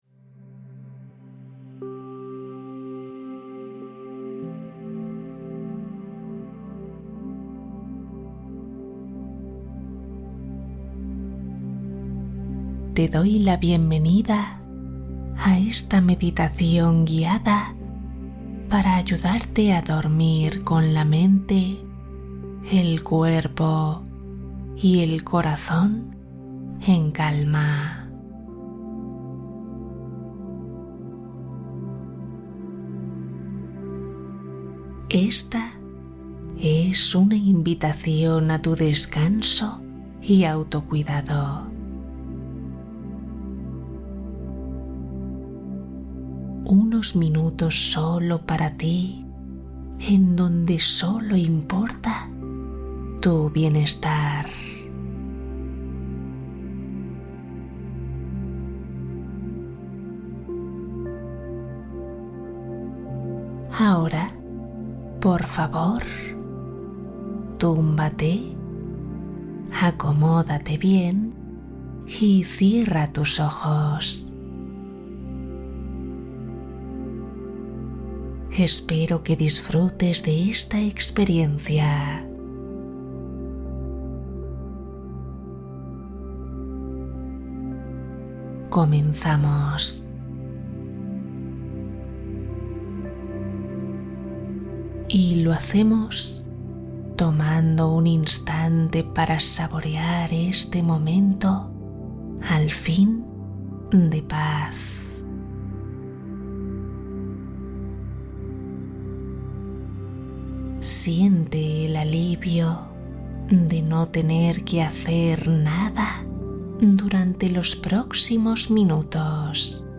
Relajación para el insomnio Meditación guiada para dormir profundamente